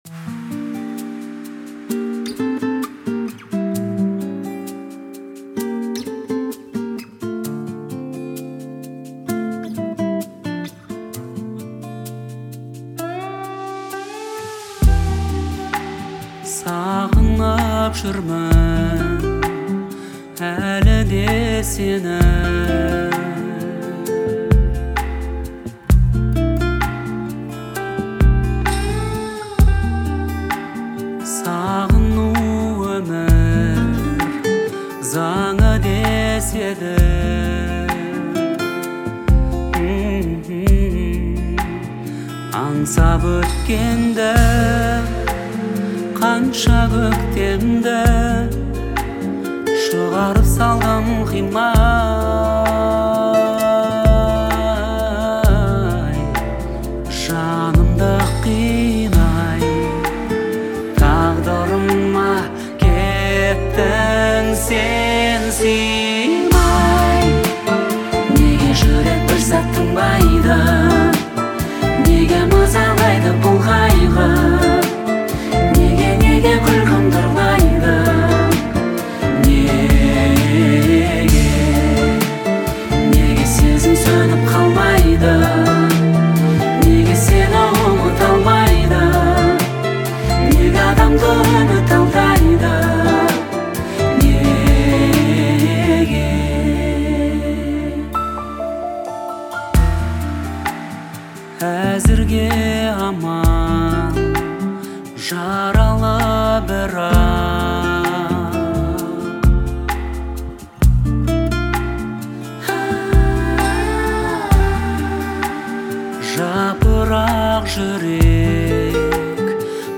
Звучание отличается мелодичностью и выразительным вокалом